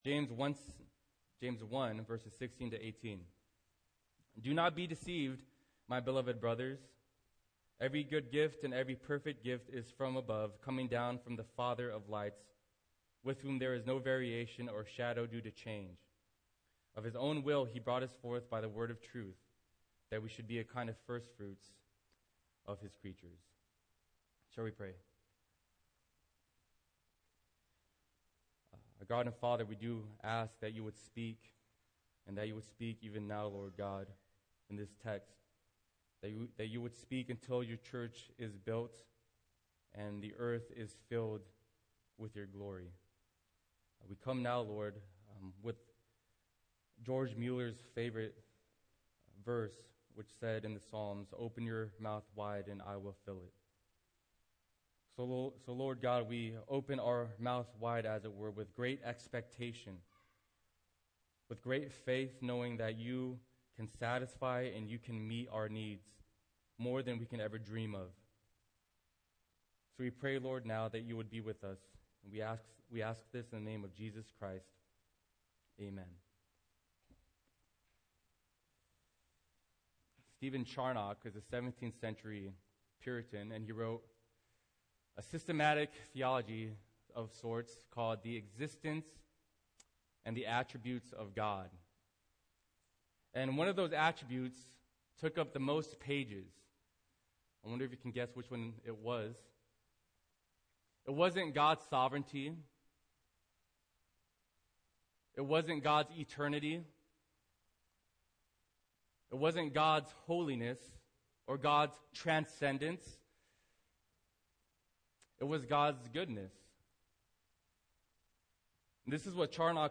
Single Sermons - Lynwood United Reformed Church - Page 2